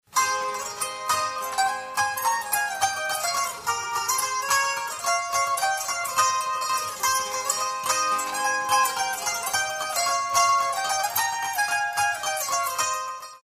CD L’atout Vosgien   Joué en duo. C’est un jeu intimiste, délicat, sur des instruments aux diapasons très courts, plectre et bâtonnet très fins.
Tonalité d’origine, tempo allegro grazioso.